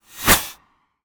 bullet_flyby_11.wav